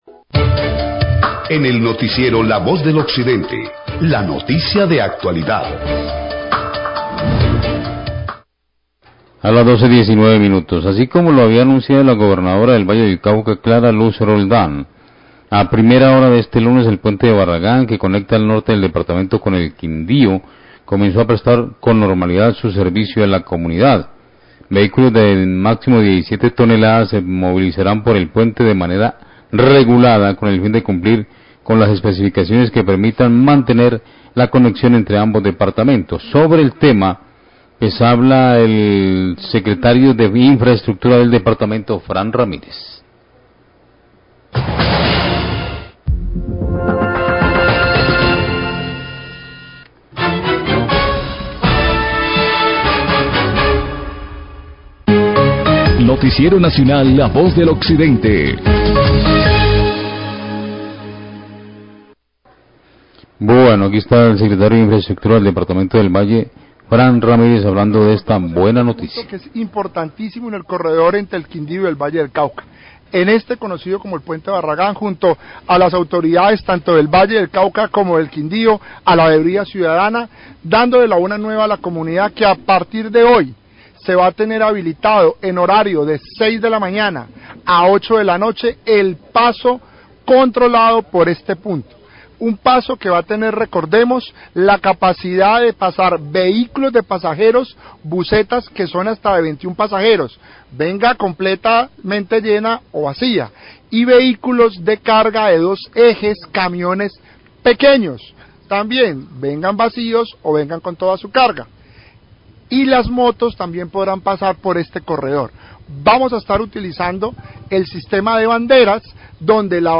Radio
El Secretario de Infraestructura del Valle, Frank Ramírez, explica la metodología que se usará para permitir el paso de vehículos sobre el puente de Barragán que fue habilitado este lunes. El Alcalde de Caicedonia, Carlos Alberto Orozco, pide paciencia a las comunidades y respeto por condiciones del paso sobre el puente.